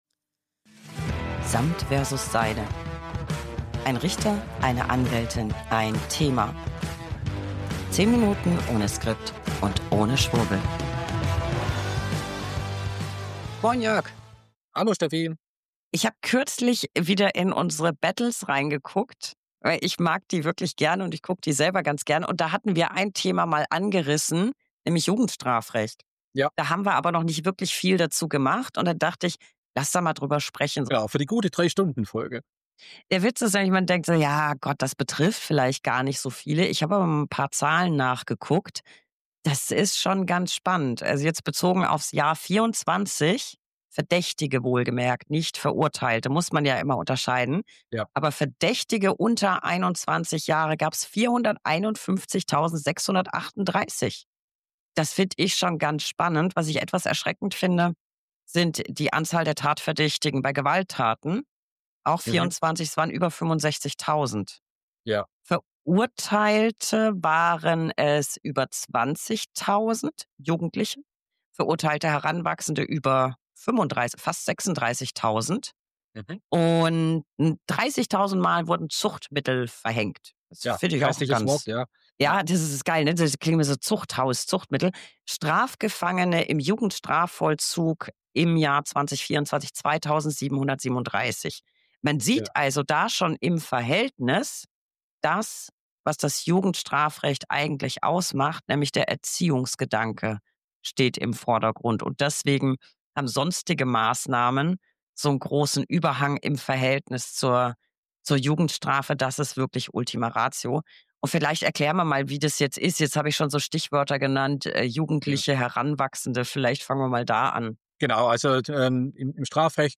1 Anwältin + 1 Richter + 1 Thema. 10 Minuten ohne Skript und ohne Schwurbel.
Beschreibung vor 7 Monaten 1 Anwältin + 1 Richter + 1 Thema. 10 Minuten ohne Skript und ohne Schwurbel.